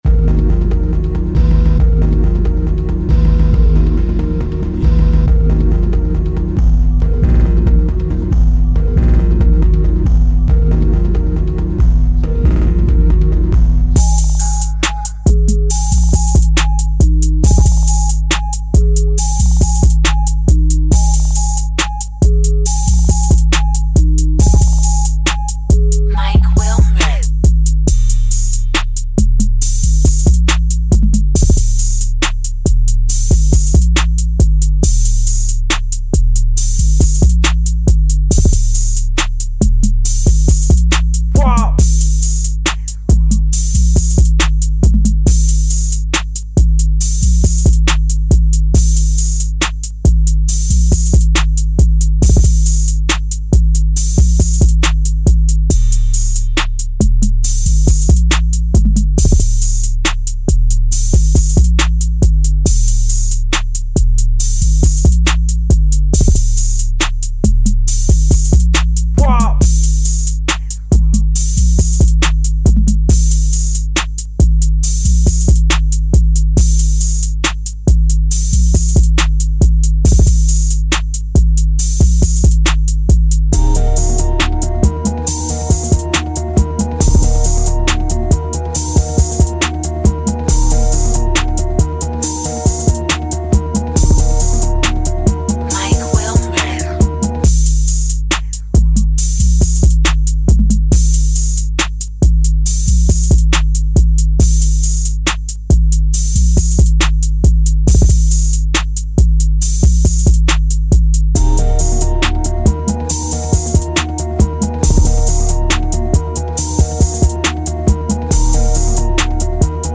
official instrumental
Trap Instrumental